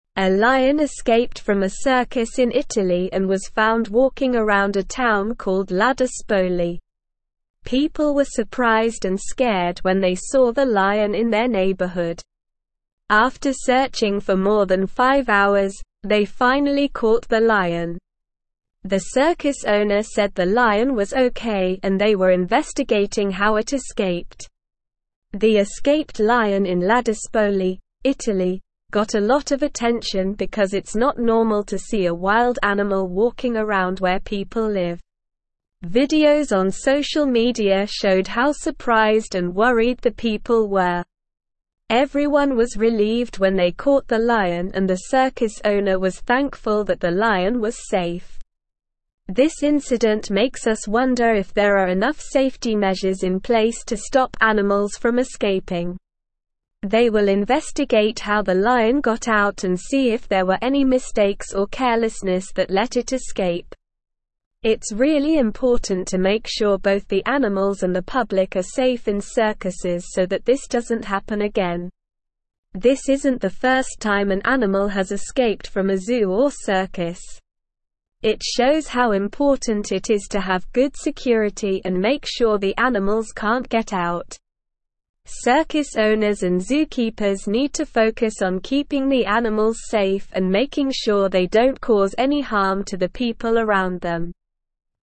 Slow
English-Newsroom-Upper-Intermediate-SLOW-Reading-Circus-Lion-Escapes-in-Italy-Causes-Town-Panic.mp3